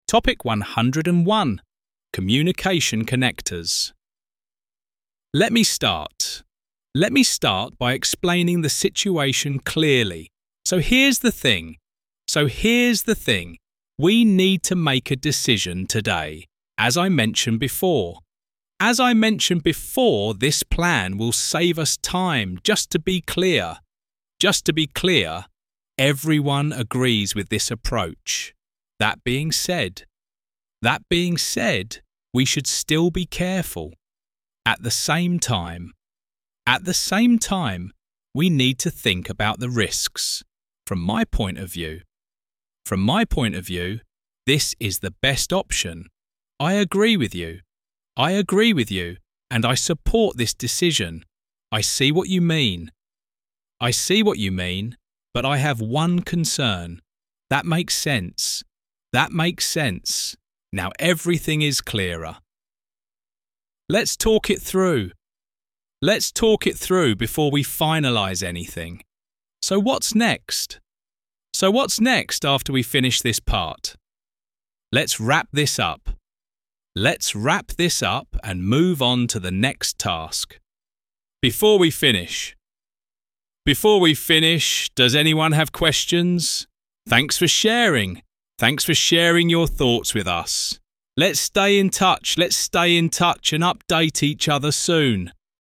Mỗi từ vựng đều đi kèm câu ví dụ thực tế, được đọc rõ ràng trong file MP3, giúp bạn hiểu và nhớ nhanh từ và vị trí của từ trong câu, biết dùng từ đó ở đâu – dùng như thế nào cho đúng.